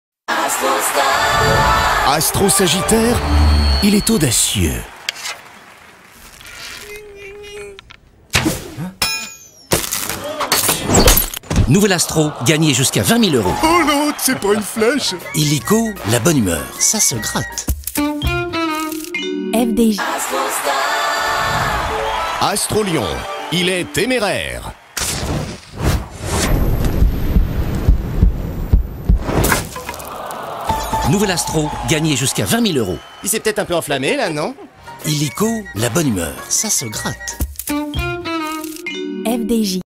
Présentateur et personnages très bêtes.
2 spot tv pour FDJ et le jeu de grattage Astro enregistré chez Studio Kouz.
Dans cette prestation, j’ai été amené à utiliser différentes hauteur de voix : médium pour certains passages, grave pour d’autres.
Le ton de cette voix-off était humoristique, drôle et parfois même un peu fou. J’ai incarné différents personnages, chacun avec sa propre personnalité, sa propre voix. En tant que présentateur, j’ai adopté un ton plus neutre, tout en conservant une pointe d’humour pour maintenir l’attention des téléspectateurs.